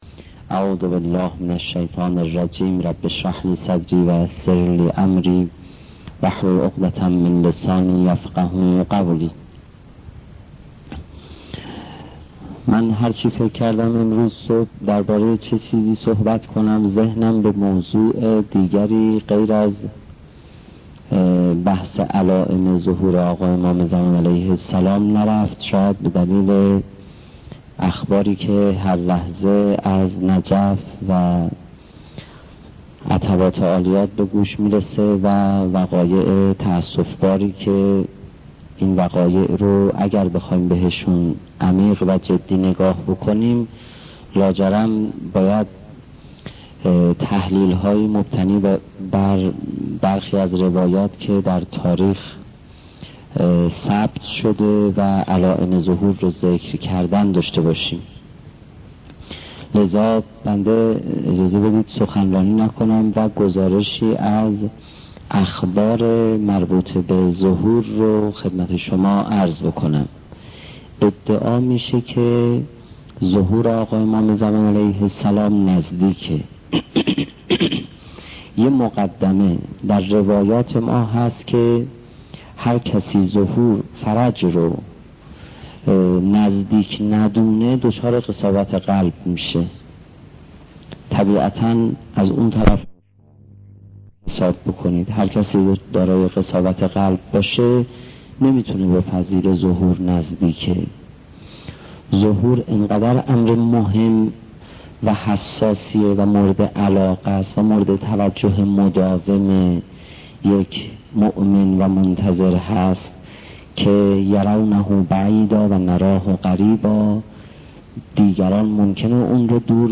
سخنرانی حجت الاسلام پناهیان درمورد علائم ظهور